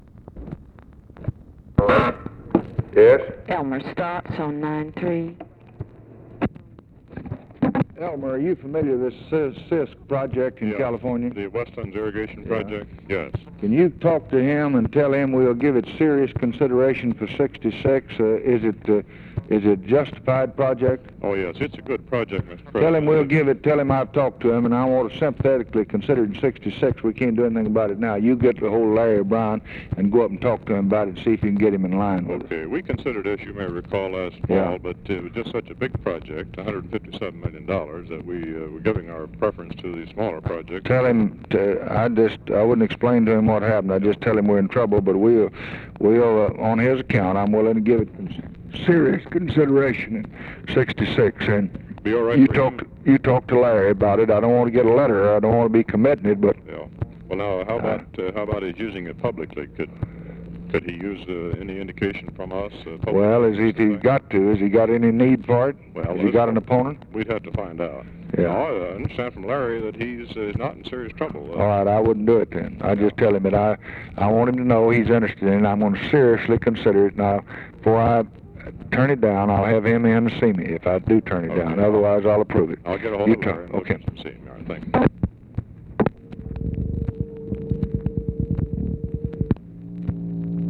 Conversation with ELMER STAATS, June 16, 1964